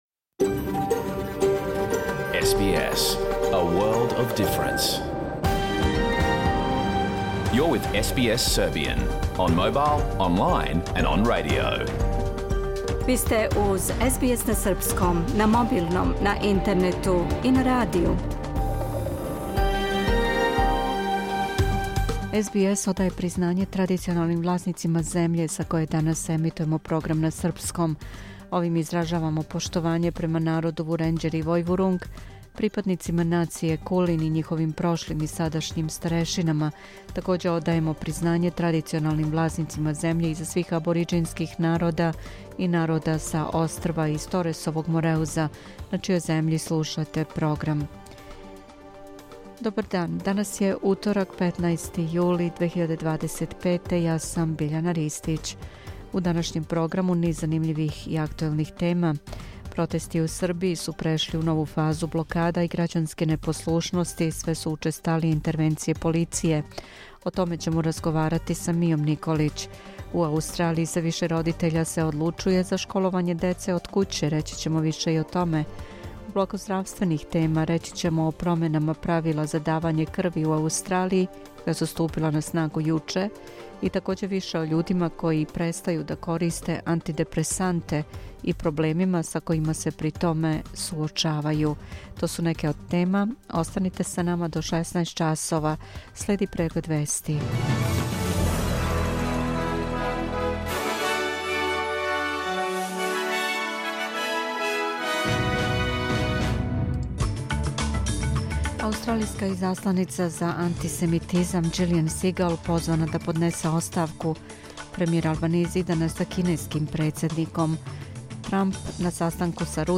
Програм емитован уживо 15. јула 2025. године